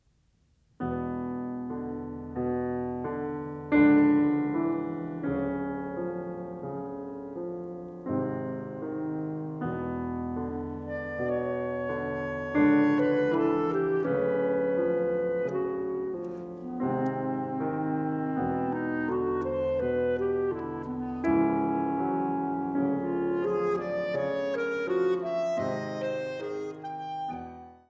In langsamer Bewegung